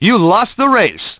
1 channel
pg-youlostrace.mp3